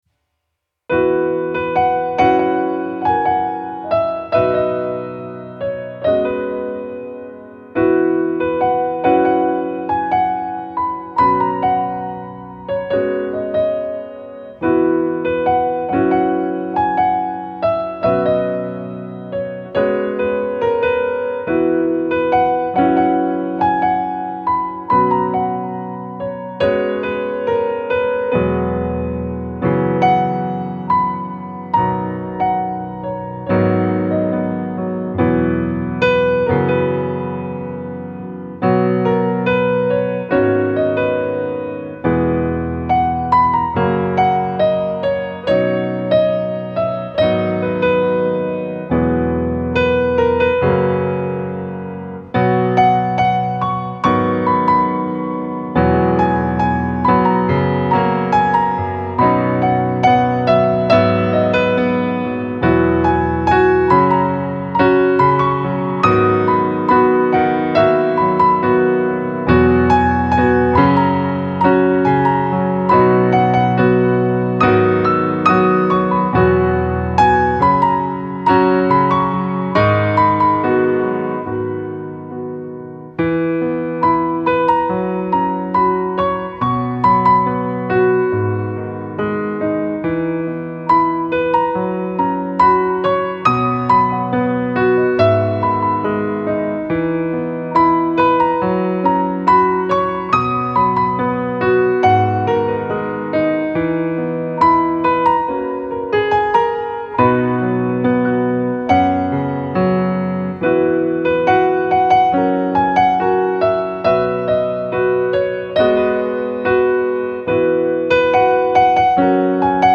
ピアノ楽曲関連